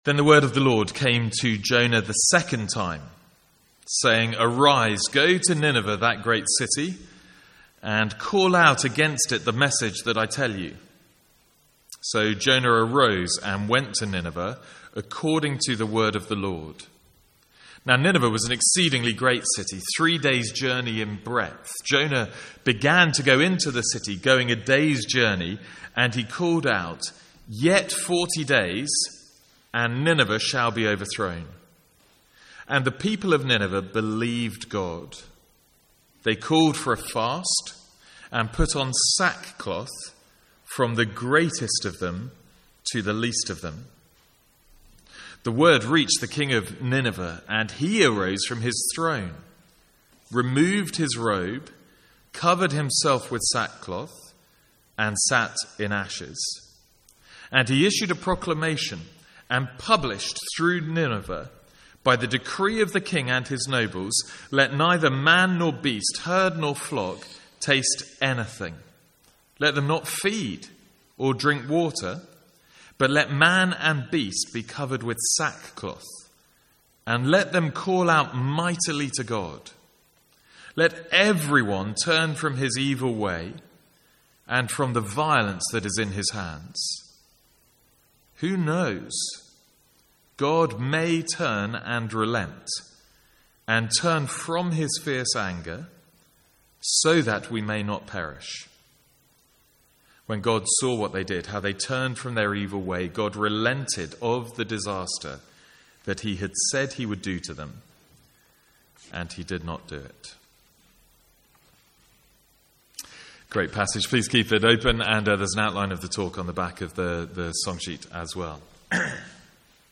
From the Sunday evening series in Jonah.